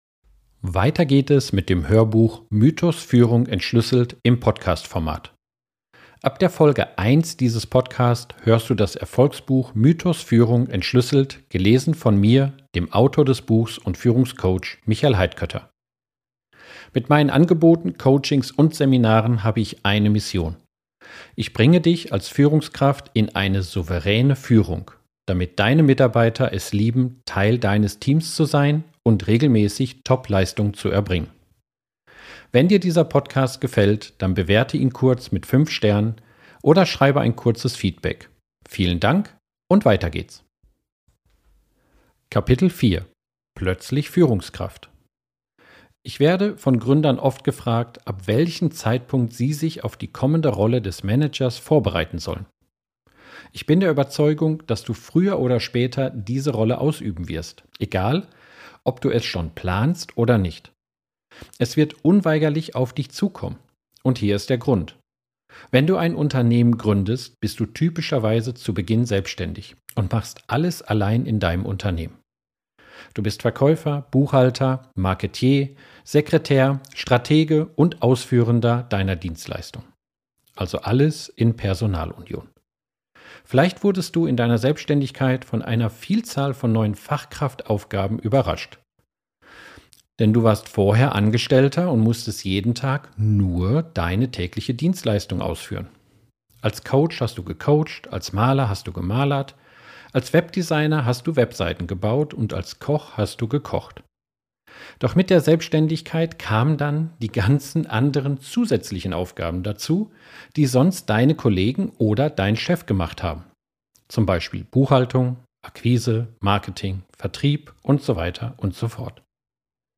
Du erhältst das vollständige Hörbuch gratis und ganz bequem im Podcast-Format zum Hören im Auto, auf Reisen, beim Sport oder im Café.